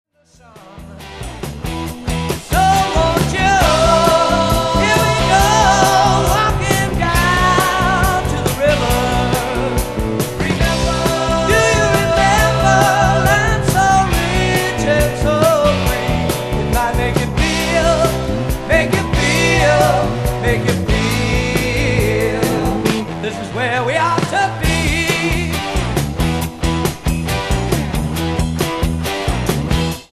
Recorded at Soundstage, Toronto.